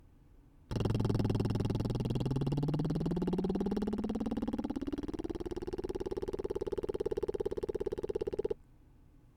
参考音源：失敗パターン
音量注意！
微妙な差なので聞き取れない場合もあるかもしれませんが、成功パターンと違い、裏声の音域に入った瞬間に音に実体感がなくなる、音色も軽くなっています。